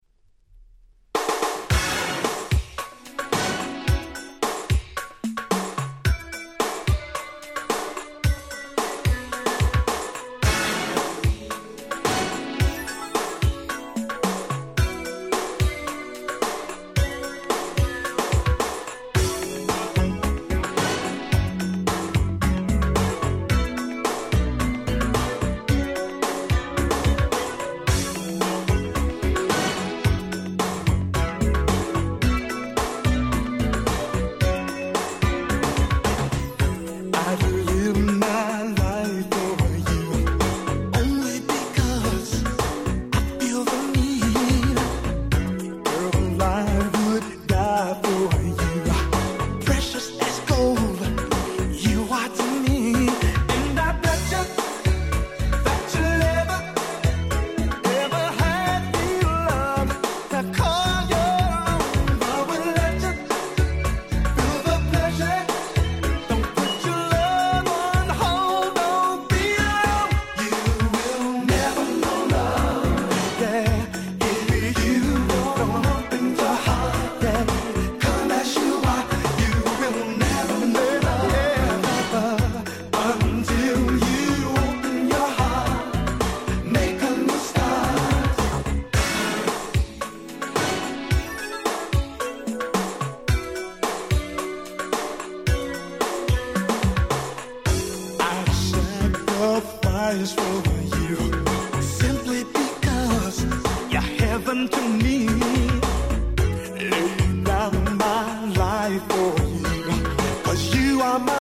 90' Super Hit R&B LP !!